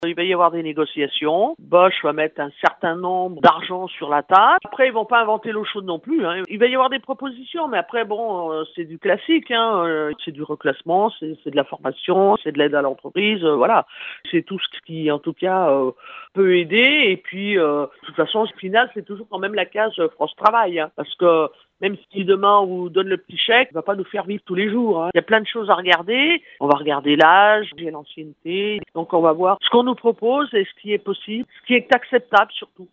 Elle nous parle de ces négociations